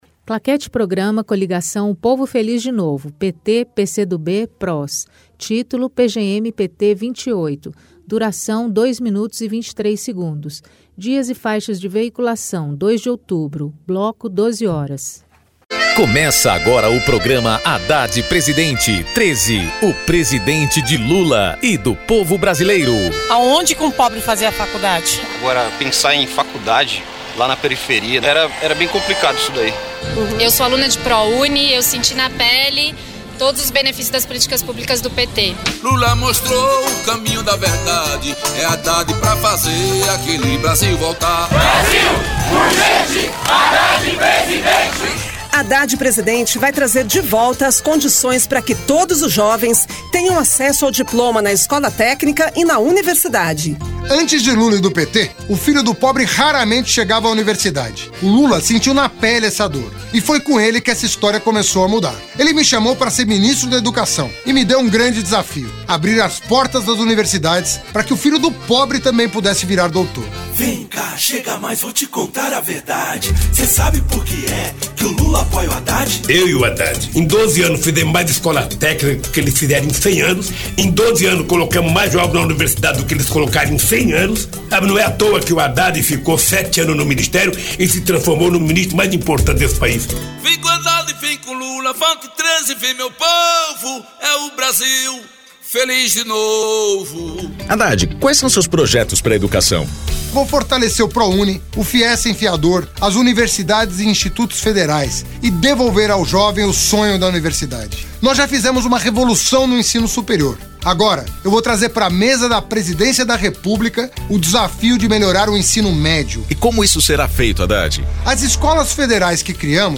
Gênero documentaldocumento sonoro
Descrição Programa de rádio da campanha de 2018 (edição 28) - 1° turno